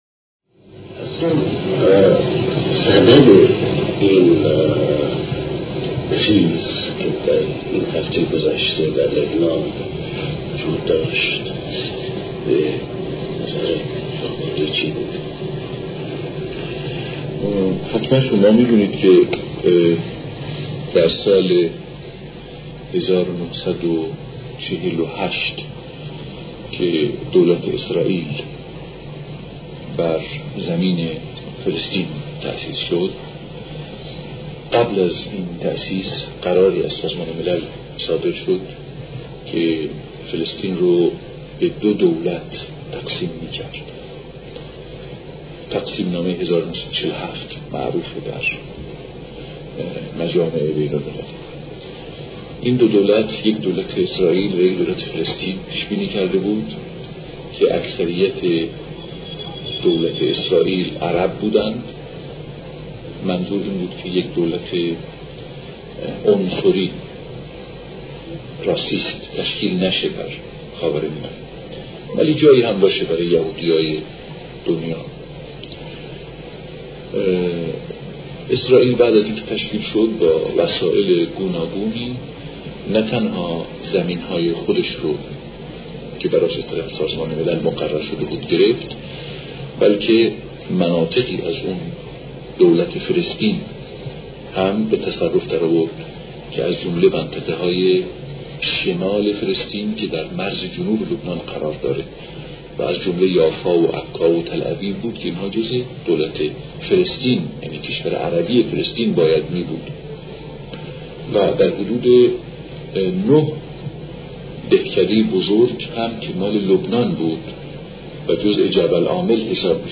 سخنرانی امام موسی صدر درباره لبنان 4 • زمان : 34:28